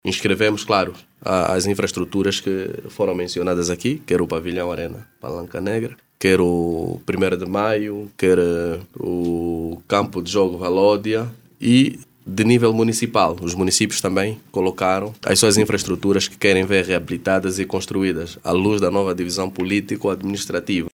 O Gabinete Provincial da Juventude e Desportos de Malanje, tem  catalogadas as infraestruturas que serão reabilitadas em 2027, em obediência à orientação do Ministério da Juventude e Desportos. Domingos Inácio,  director provincial, fala do processo que vai abranger os 27 municípios da província.